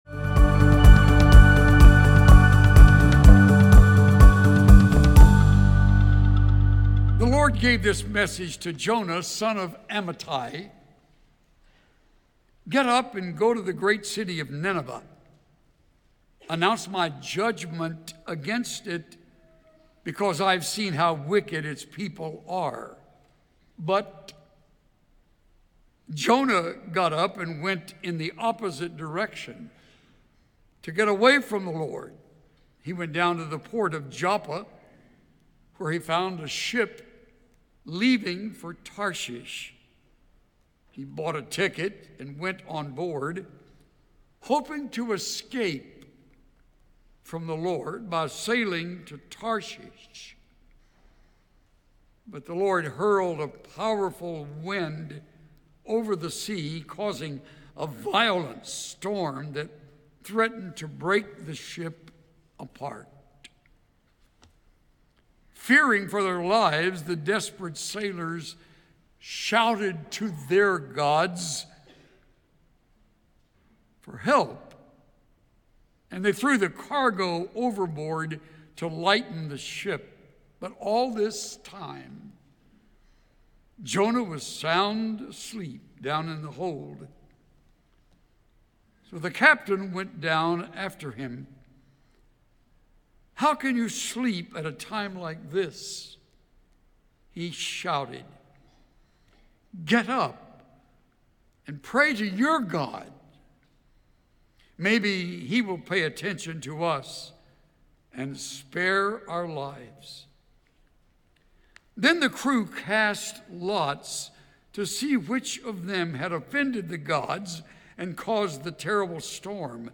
Tune in for an inspiring morning of worship led by our Wind Symphony, followed by the next message in Pastor Chuck Swindoll’s series…
Listen to Message